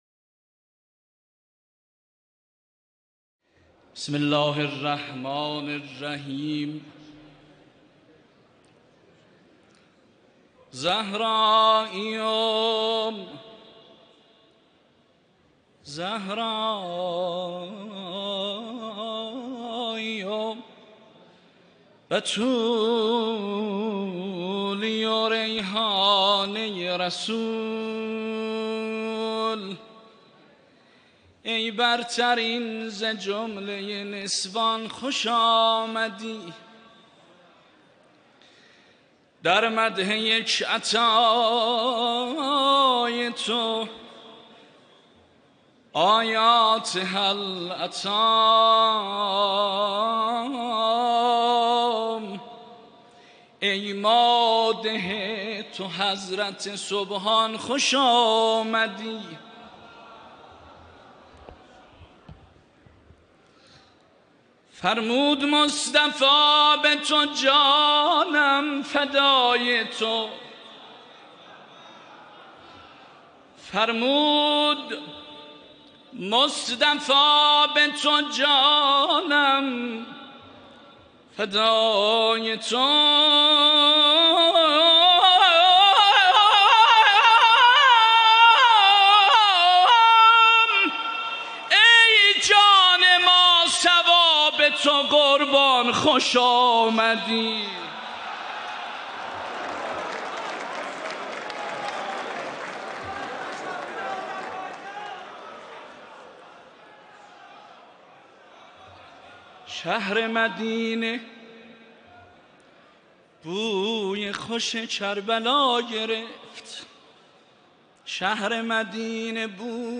مولودی آذری مولودی ترکی
در محضر رهبر معظم انقلاب